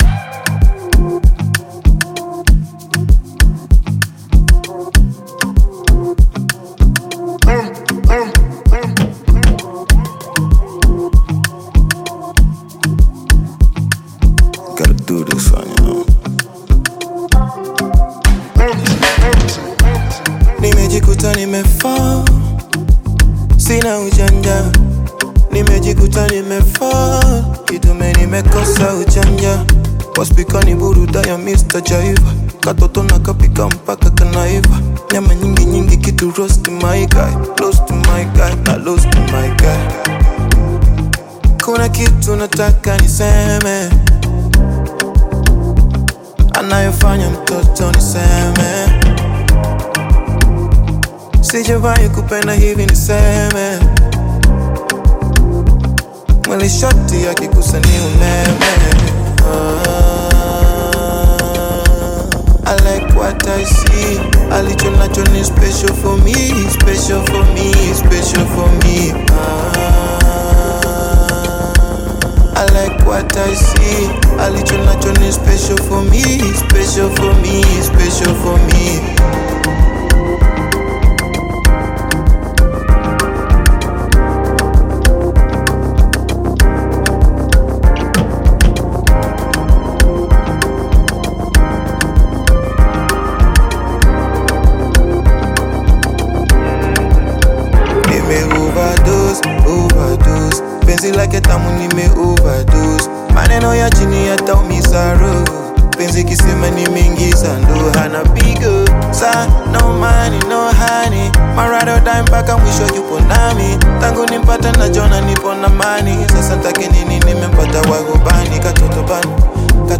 Bongo Flava music track
Bongo Flava song